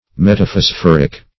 Meaning of metaphosphoric. metaphosphoric synonyms, pronunciation, spelling and more from Free Dictionary.
Search Result for " metaphosphoric" : The Collaborative International Dictionary of English v.0.48: metaphosphoric \met`a*phos*phor"ic\, a. [Pref. meta- + phosphoric.]